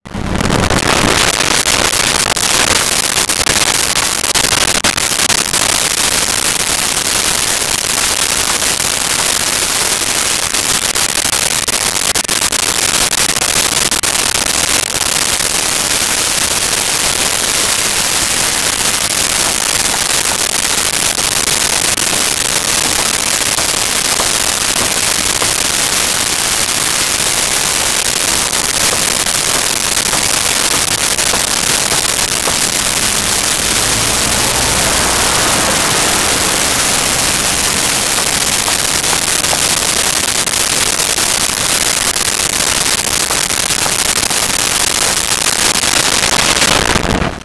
Construction Site
Construction Site.wav